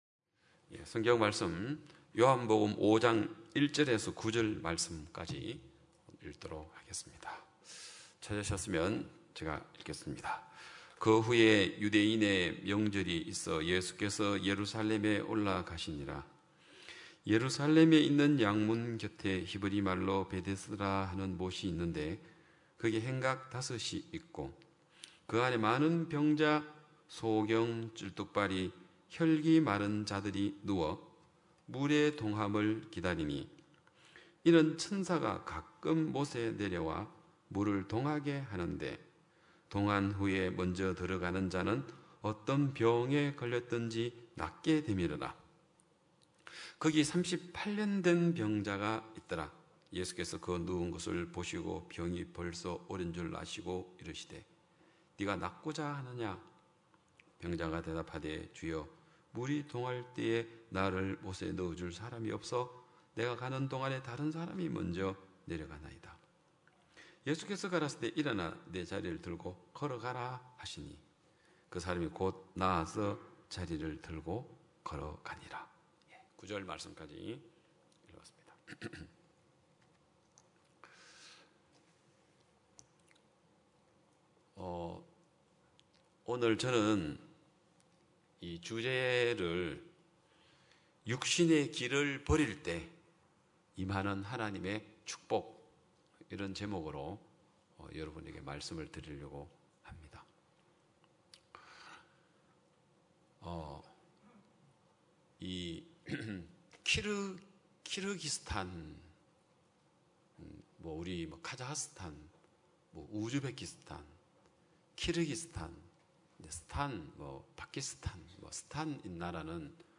2022년 7월 31일 기쁜소식양천교회 주일오전예배
성도들이 모두 교회에 모여 말씀을 듣는 주일 예배의 설교는, 한 주간 우리 마음을 채웠던 생각을 내려두고 하나님의 말씀으로 가득 채우는 시간입니다.